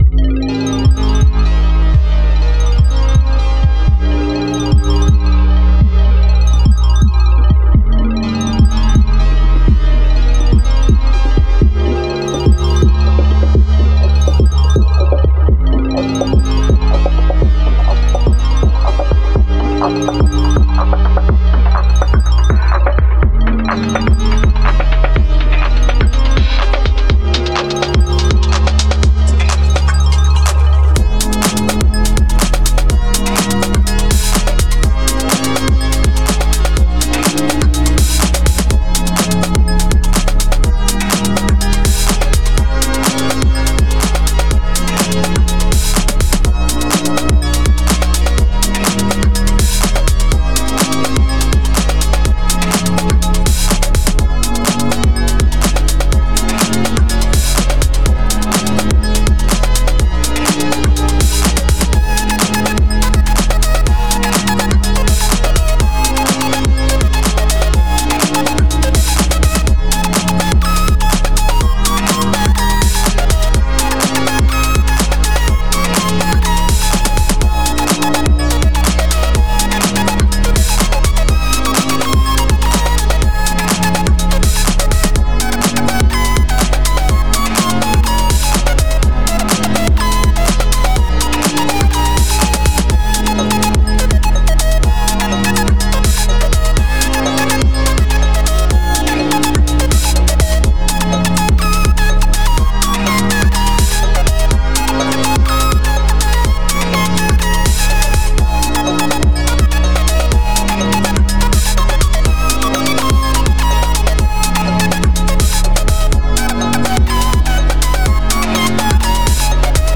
124 BPM